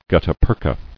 [gut·ta-per·cha]